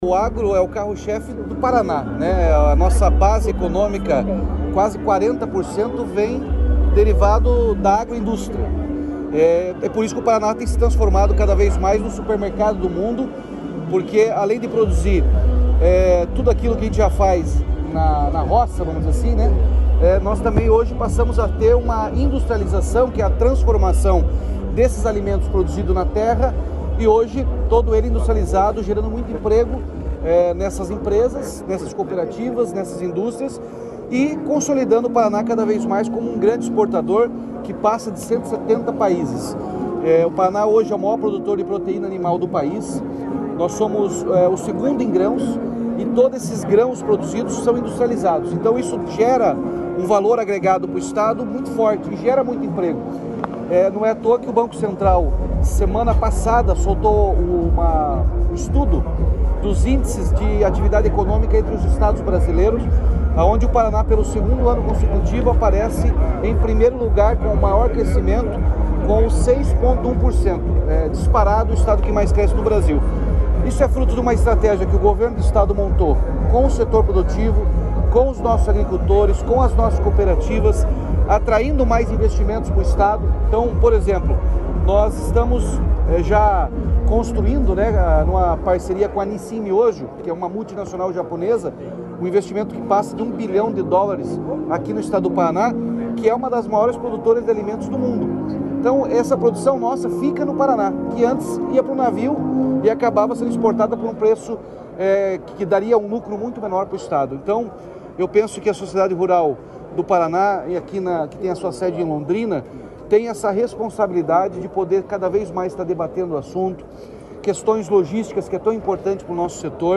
Sonora do governador Ratinho Junior durante o Fórum do Agronegócio
RATINHO JUNIOR - FORUM AGRONEGÓCIO.mp3